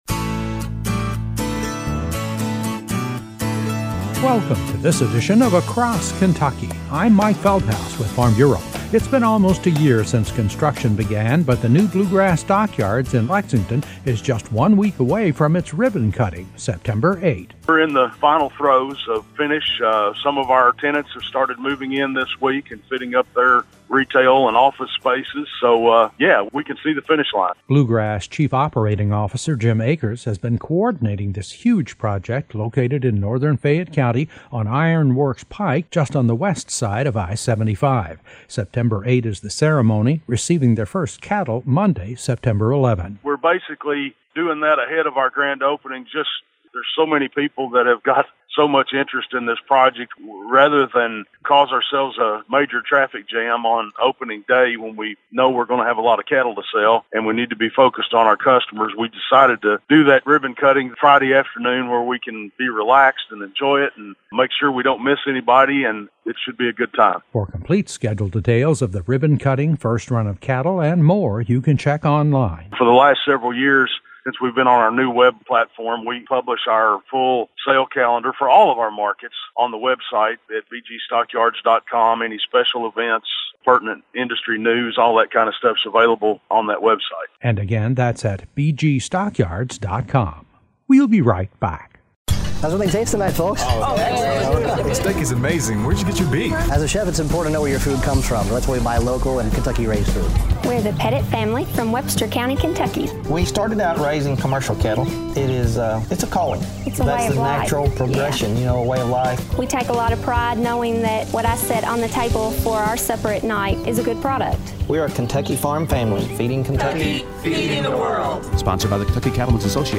A feature report on the newly rebuilt Bluegrass Stockyards in Lexington, which will hold a ribbon cutting on Sep 8, and accept its first run of cattle on Sep 11.